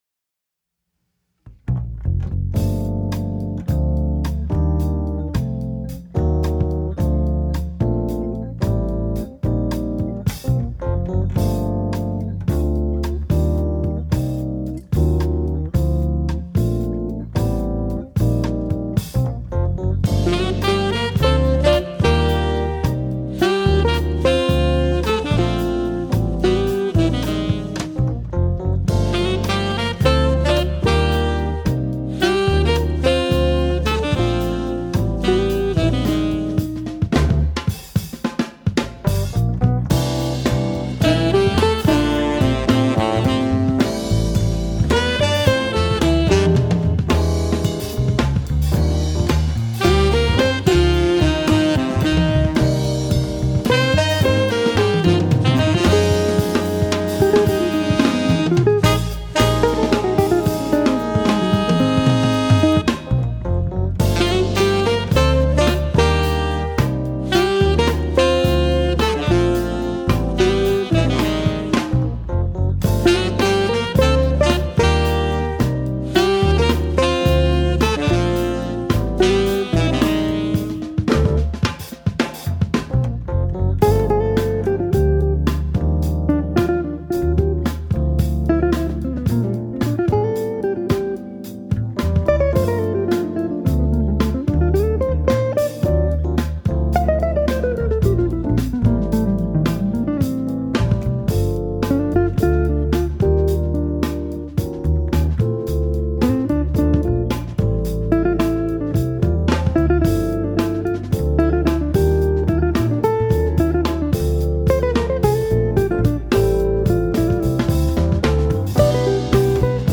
Bass Guitar